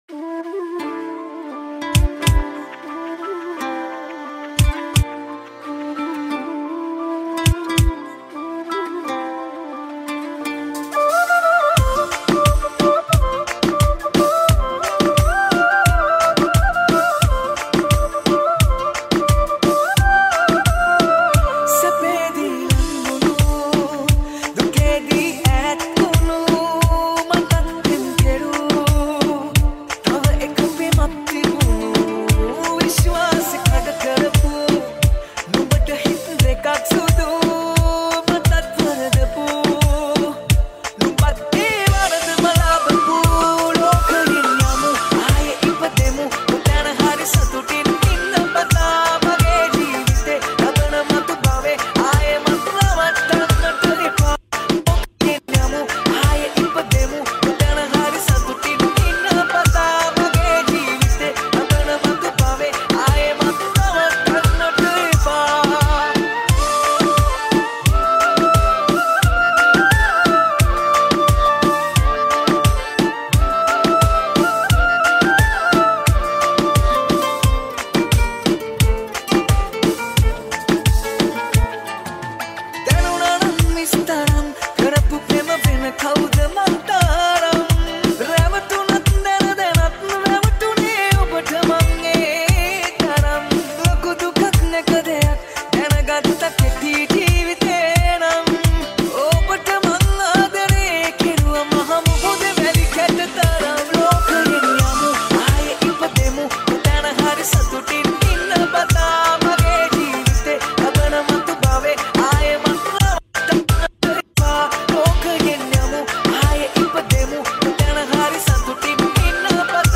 BPM 87